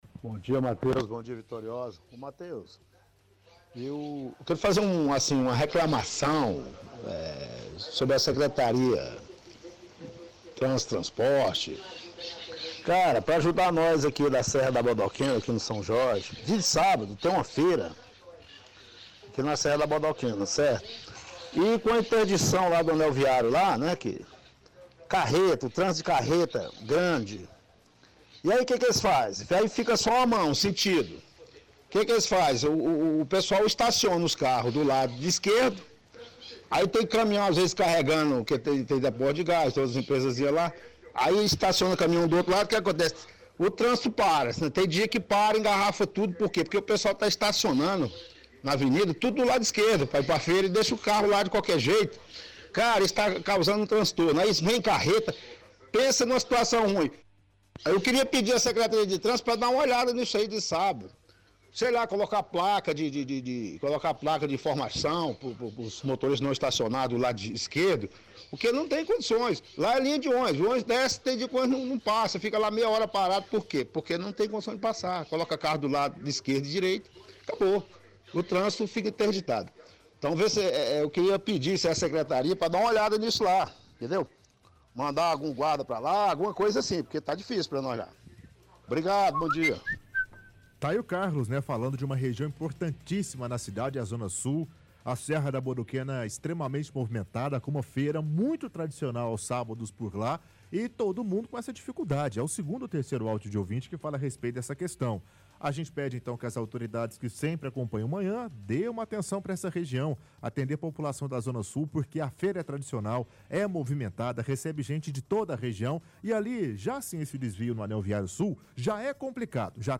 – Ouvinte pede ajuda da Settran pois o trânsito fica ruim no São Jorge em dia de feira aos sábados em função do desvio do trafego vindo do anel viário sul. Afirma que o local é linha de trânsito que por muitas vezes não consegue passar.